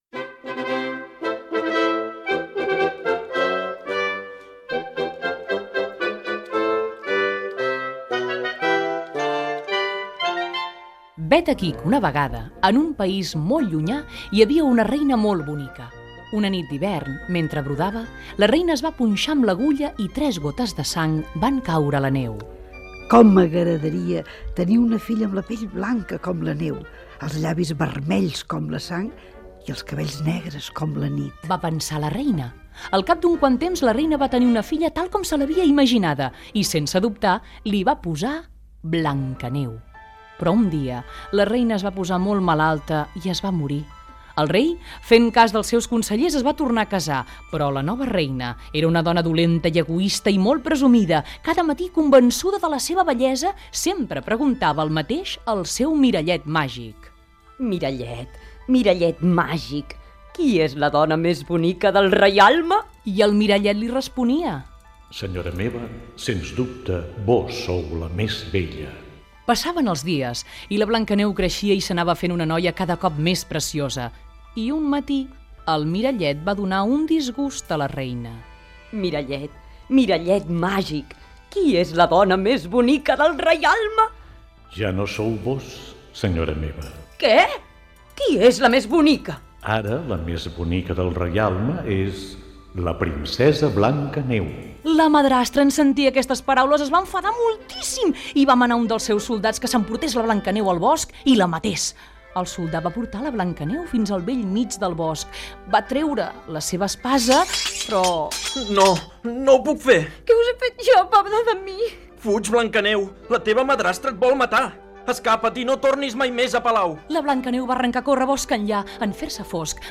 Narració del conte "Blancaneu i els set nans"